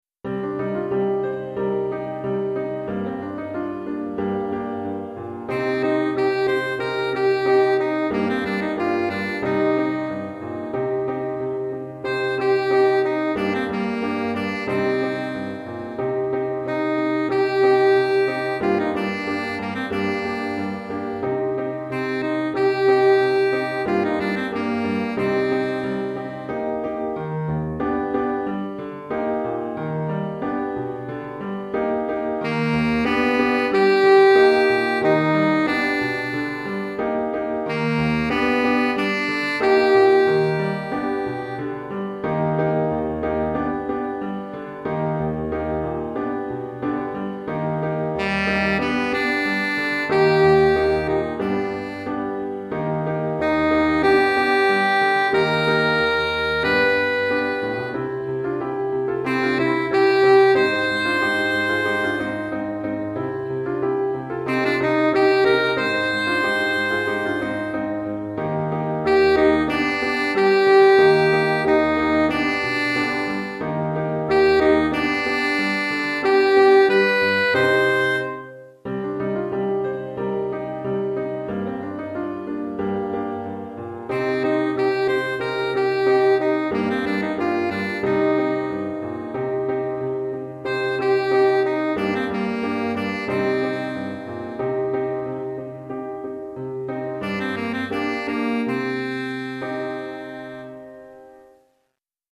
Saxophone Alto et Piano